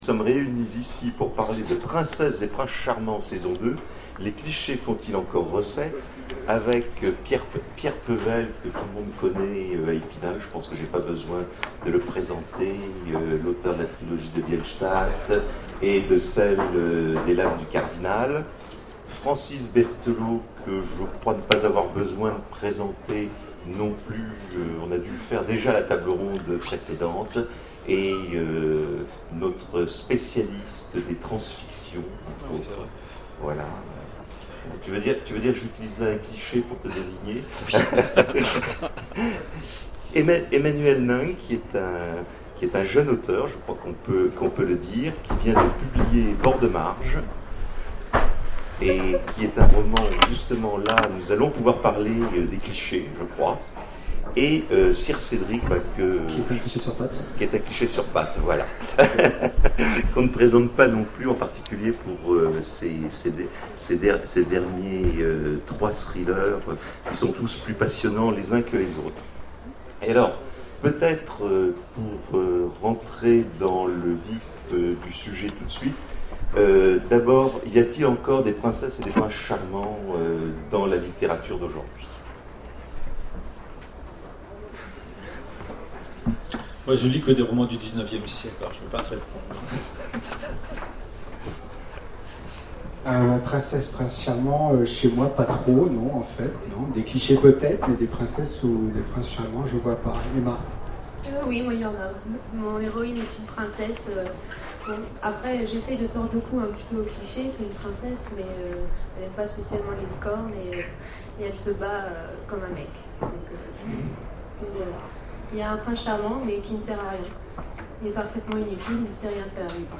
Imaginales 2012 : Conférence Princesses et princes charmants, saison 2